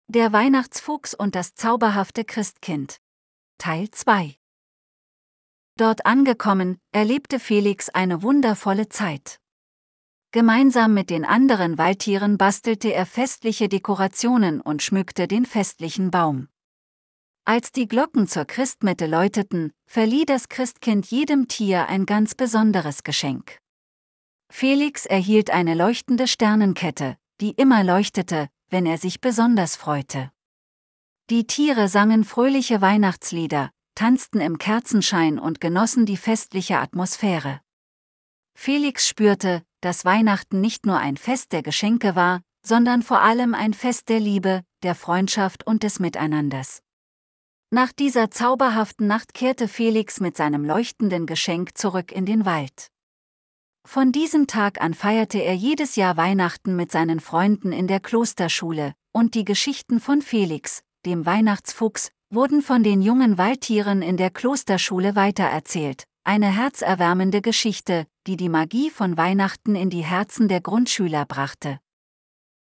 Geschichte, Bild und Voiceover generiert von GenAI.